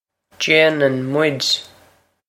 Pronunciation for how to say
jain-on mwid
This is an approximate phonetic pronunciation of the phrase.